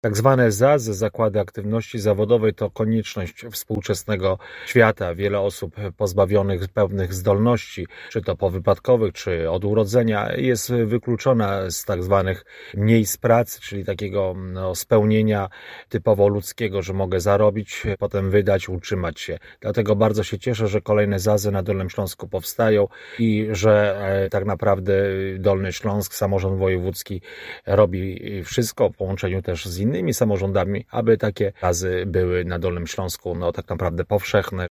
-Chcemy, by na Dolnym Śląsku każdy miał dostęp do tzw. ZAZ-ów – mówi Andrzej Kredkowski -Wiceprzewodniczący Sejmiku Województwa Dolnośląskiego.